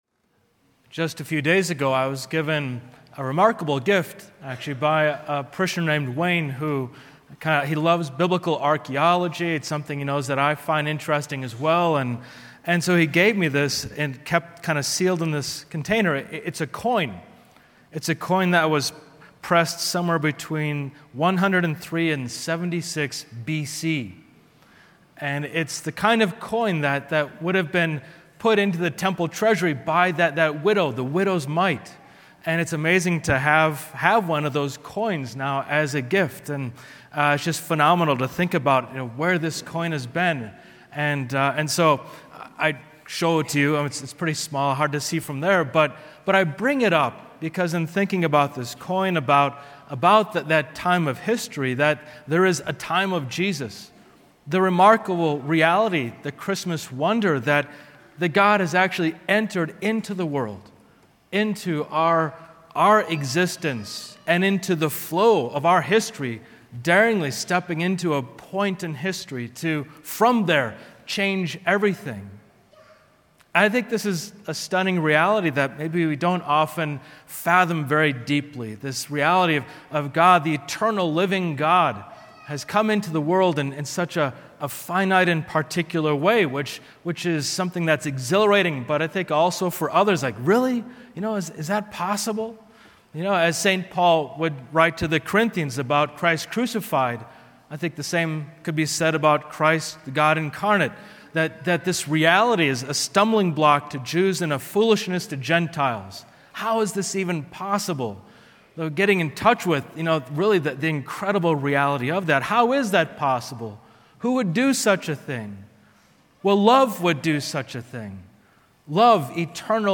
08 Jan Christmas Day Homily